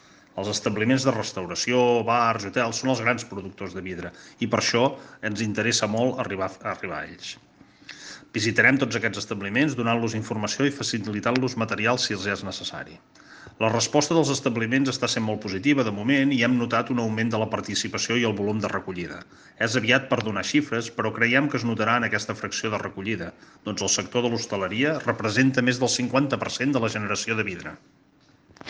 La participació i recollida de vidre a Torroella de Montgrí ha augmentat després que Ecovidrio hagi visitat durant les darreres setmanes un total de 84 establiments de restauració de Torroella i l’Estartit per sensibilitzar-los, ajudar-los en la millora del reciclatge del vidre i fomentar el servei de recollida porta a porta. Ho explica a Ràdio Capital el regidor de Serveis Municipals i Residus, Marc Calvet.